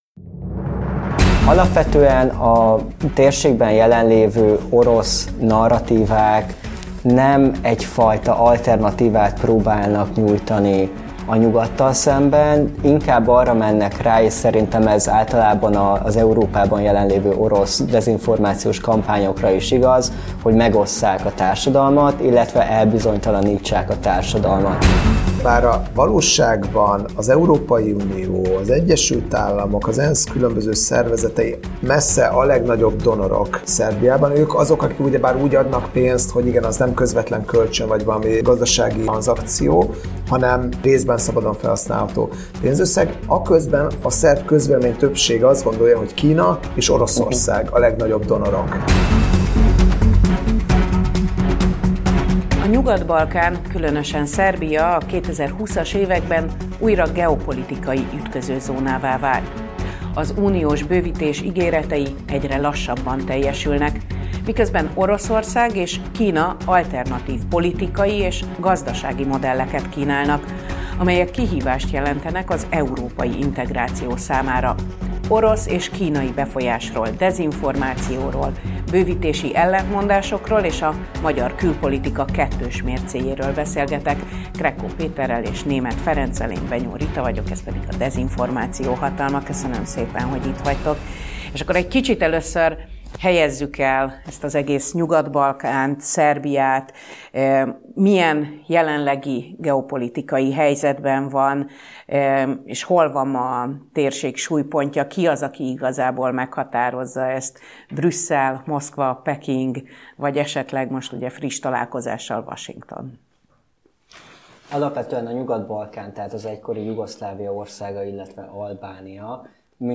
Cser-Palkovics András Székesfehérvár fideszes, és Csőzik László Érd ellenzéki polgármestere volt a Szabad Európa podcastjá...